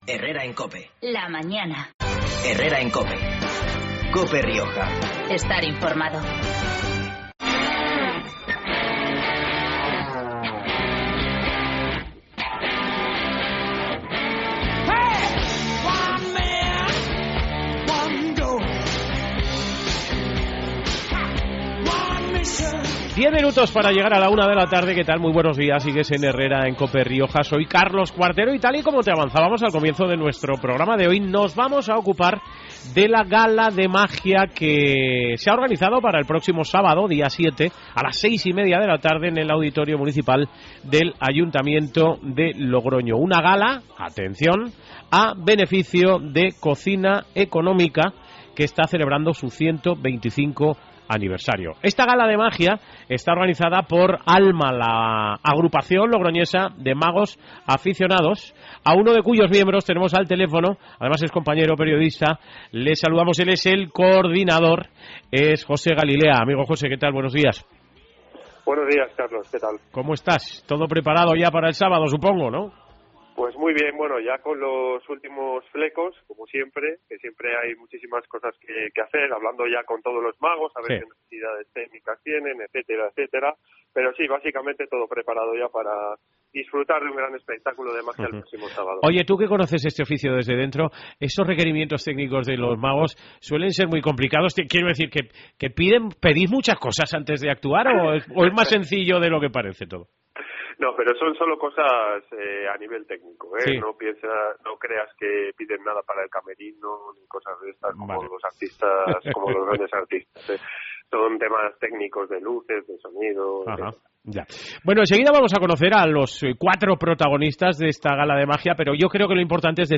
Herrera en Cope Rioja (lunes, 2 septiembre. 12:50-13:00 horas)